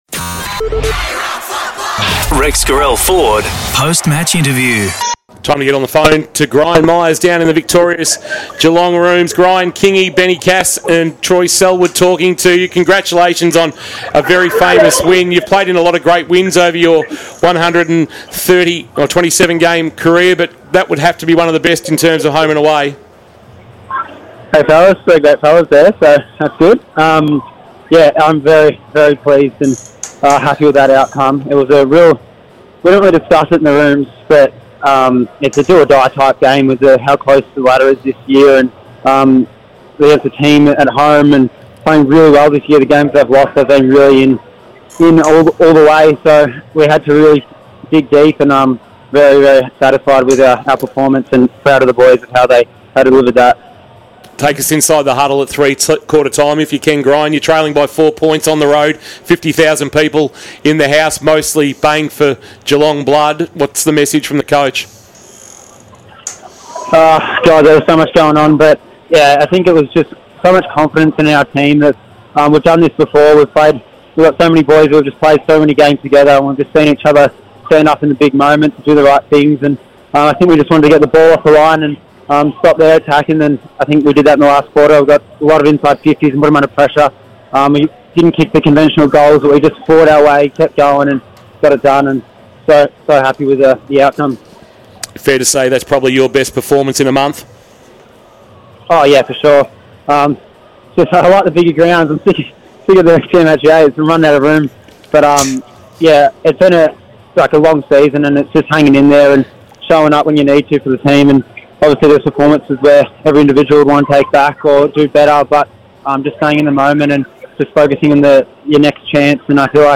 2024 - AFL - Round 21 - Fremantle vs. Geelong - Post-match interview: Gryan Miers (Geelong)